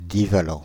Ääntäminen
Synonyymit (kemia) bivalent diatomique Ääntäminen France (Île-de-France): IPA: /di.va.lɑ̃/ Haettu sana löytyi näillä lähdekielillä: ranska Käännöksiä ei löytynyt valitulle kohdekielelle.